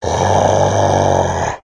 zombie.ogg